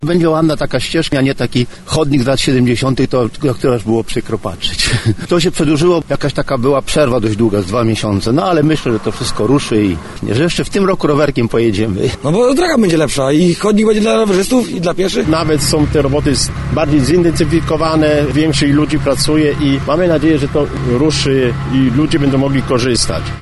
Wreszcie będziemy mogli bezpiecznie poruszać się po tym odcinku trasy – komentują mieszkańcy.
27mieszkancy.mp3